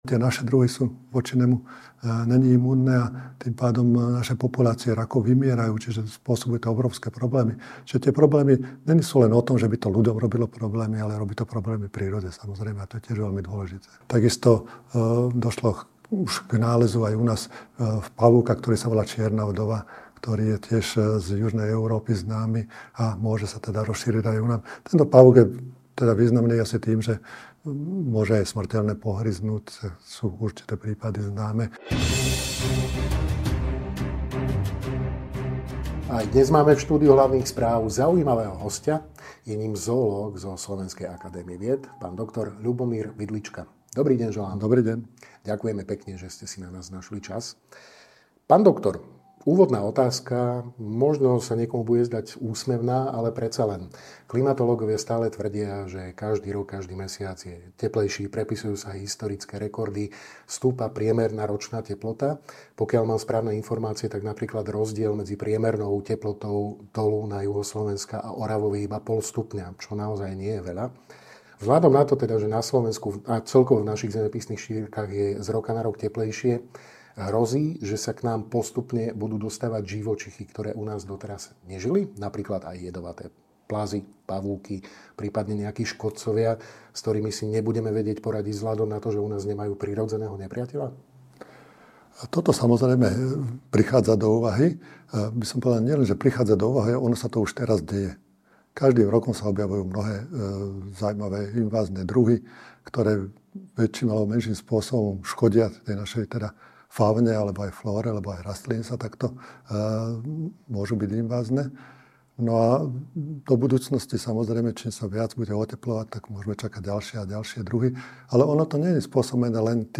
O tejto, ale aj iných zaujímavých témach, týkajúcich sa flóry, fauny, aj našej planéty, sme sa rozprávali so zoológom Slovenskej akadémie vied, doc.